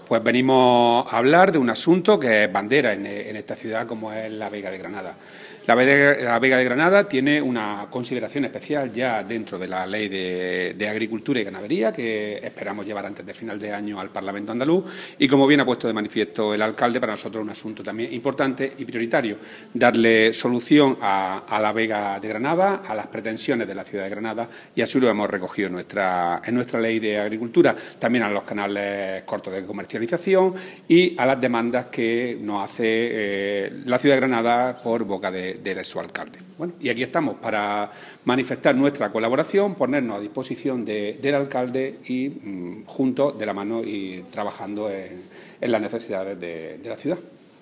Declaraciones de Rodrigo Sánchez sobre la Vega de Granada